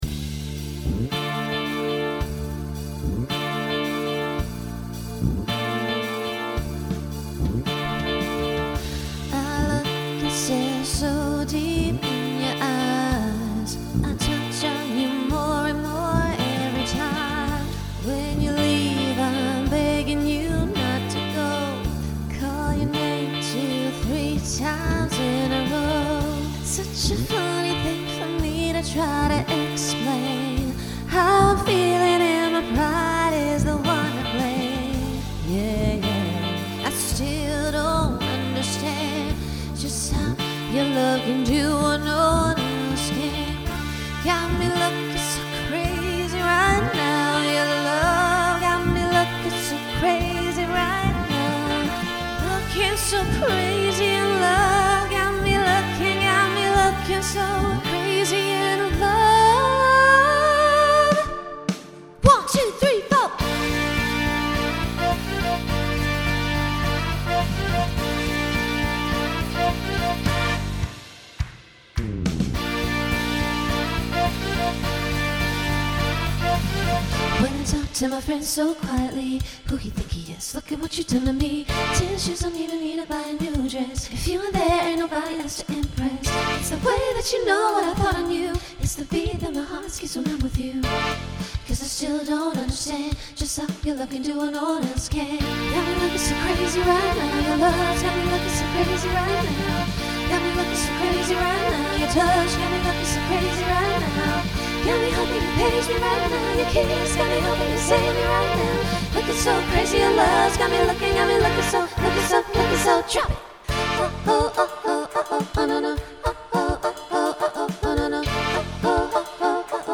Genre Pop/Dance Instrumental combo
Transition Voicing SSA